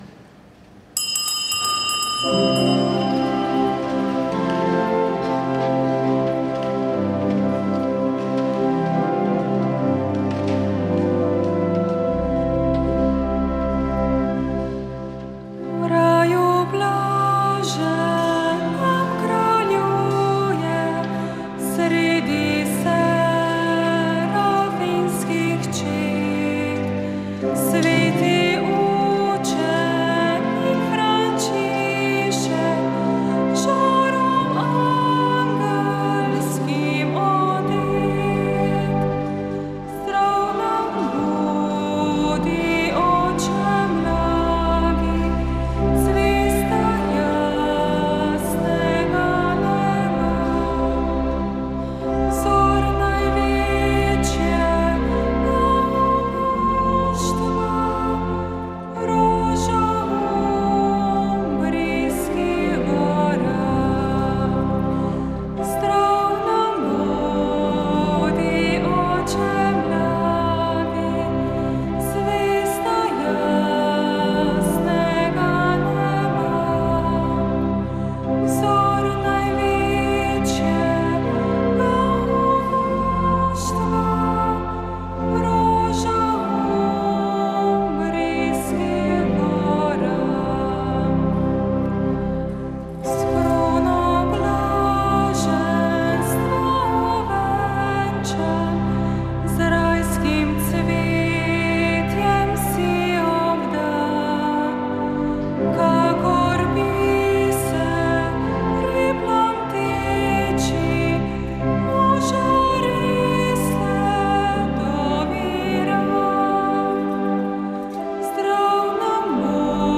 Sveta maša
Sv. maša iz cerkve Marijinega oznanjenja na Tromostovju v Ljubljani 3. 10.